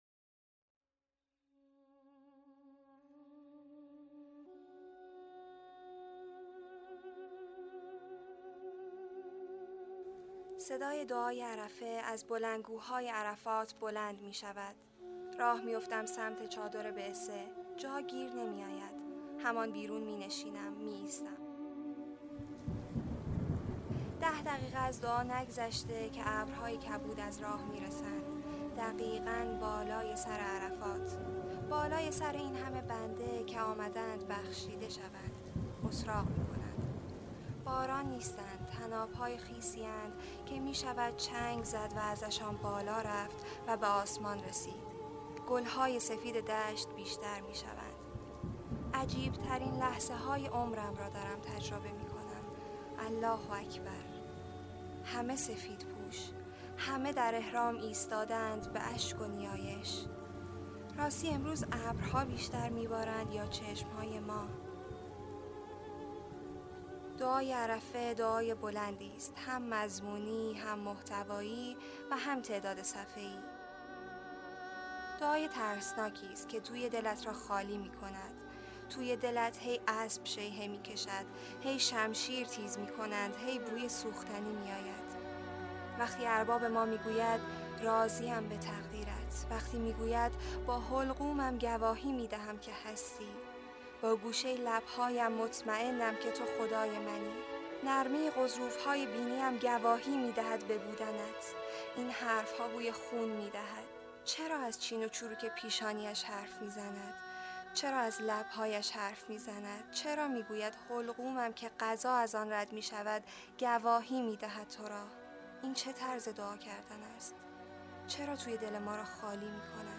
در میانه دعای عرفه فرازهایی وجود دارد که انگار توی دل آدم را خالی می‌کند؛ امام حسین(ع) حرف‌های عجیبی به خدا می‌زند که مرور آن از رازی پرده برمی‌دارد. گروه چندرسانه‌ای ایکنا به مناسبت روز عرفه بخشی از کتاب «خال سیاه عربی» را، که سفرنامه‌ای جالب است، به صورت صوتی تهیه کرده است؛ با هم می‌شنویم.
برچسب ها: خال سیاه عربی ، پادکست روز عرفه ، روز عرفه ، کتاب خوانی ، نیایش امام حسین در روز عرفه